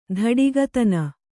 ♪ dhaḍigatana